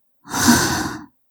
语气词-难过_(2).wav